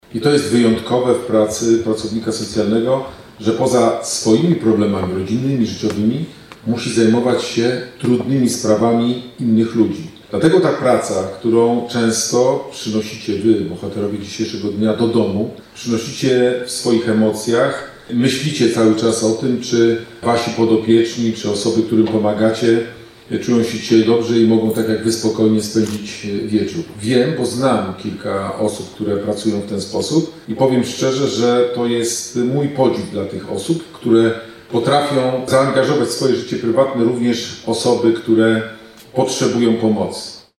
Tegoroczna uroczystość odbyła się w Teatrze Polskim w Szczecinie.
„To święto osób, które angażują się w życie innych” – podkreślał z kolei wagę tego dnia Wojewoda Zachodniopomorski Adam Rudawski.